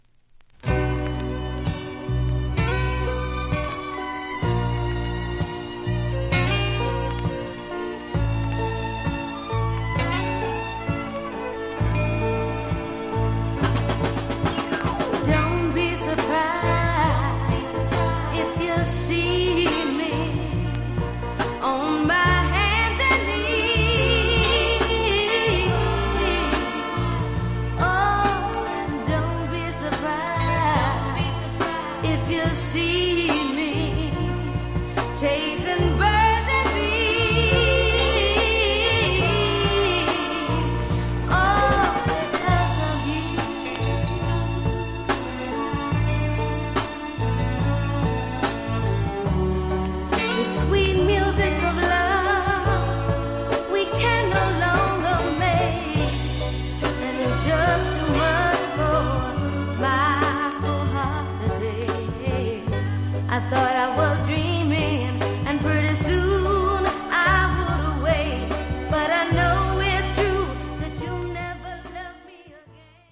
マイアミのソウルグループ。
実際のレコードからのサンプル↓